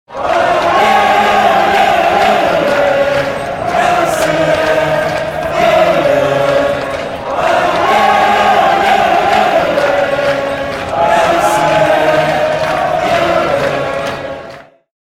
Звуки хоккея
Оле-оле, оле, Россия вперед